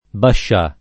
pascià [ pašš #+ ]